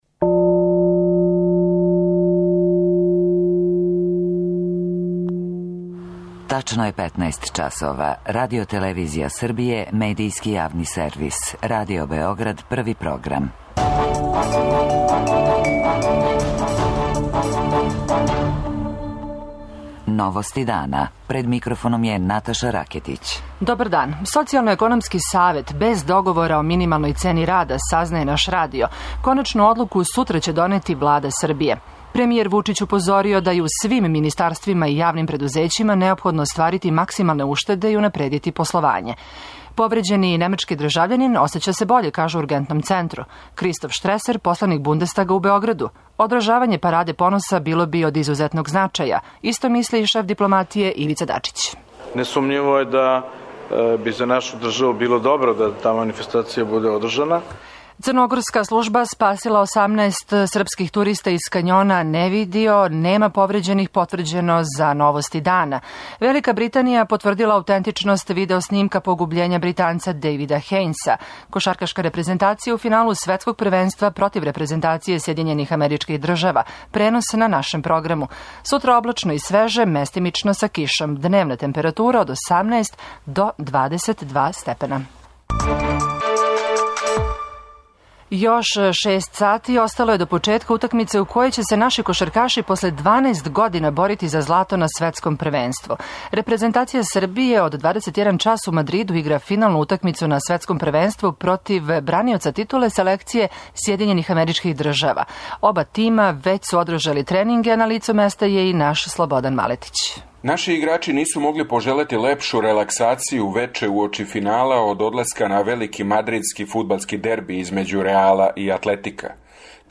Утакмицу можете пратити на нашем програму, а наш репортер из Мадрида јавља каква су очекивања наших репрезентативаца.
преузми : 15.27 MB Новости дана Autor: Радио Београд 1 “Новости дана”, централна информативна емисија Првог програма Радио Београда емитује се од јесени 1958. године.